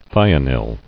[thi·o·nyl]